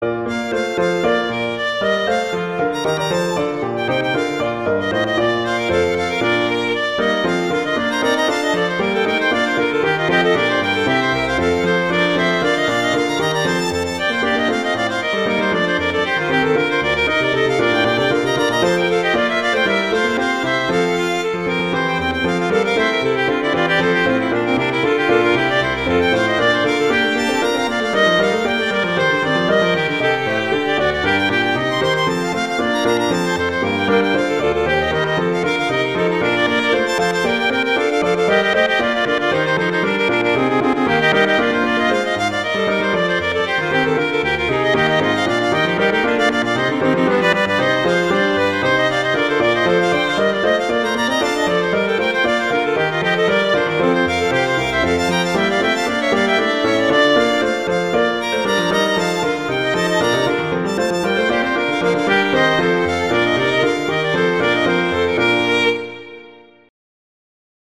classical
G minor
Allegro